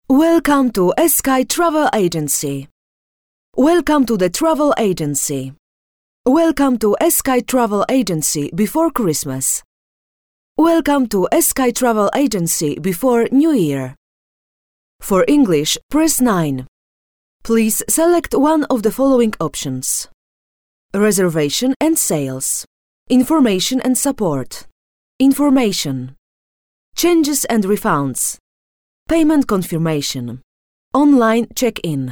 Female 30-50 lat
Voice artist and actress speaking three languages.
Nagranie lektorskie